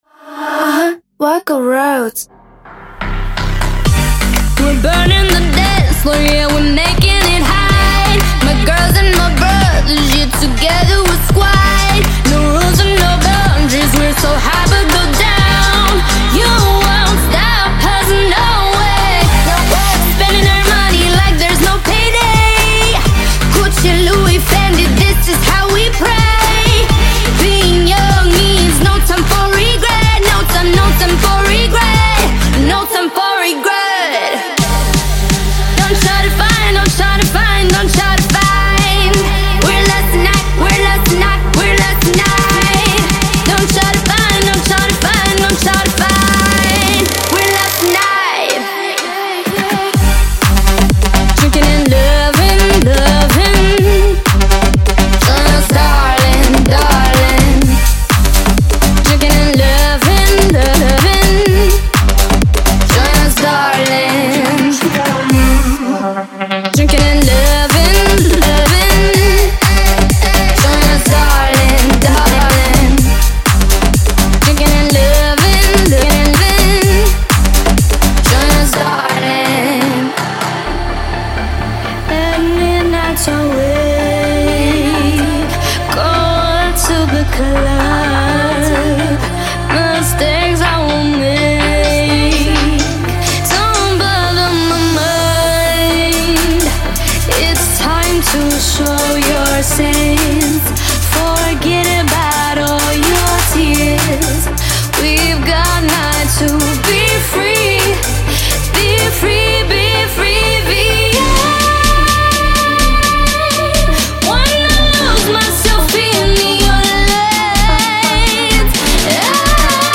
2026-01-03 Electro House 165 推广
专注于电子浩室音乐和充满灵魂的歌声，必将触动您的心灵